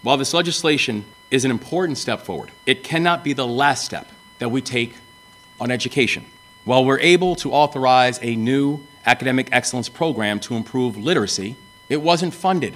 Governor Moore added that the work is not done with regard to public school education in Maryland…